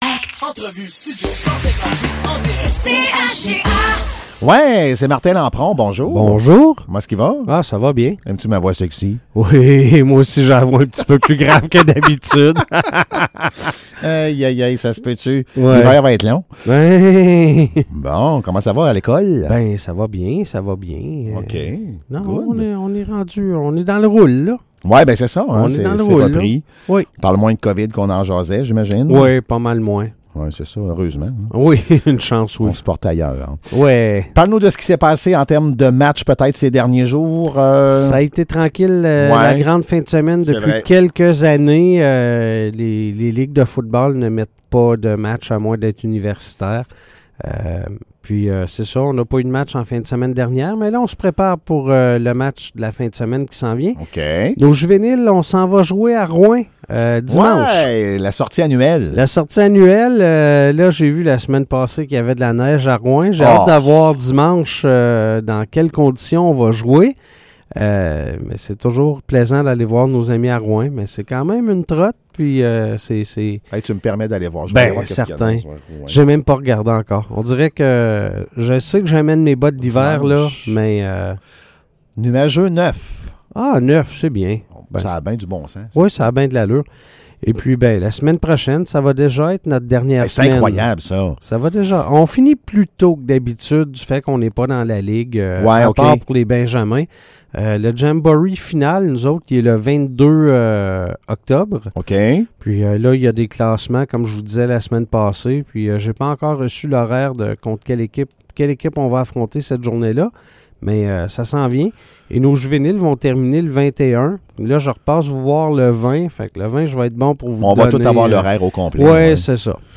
Chronique sports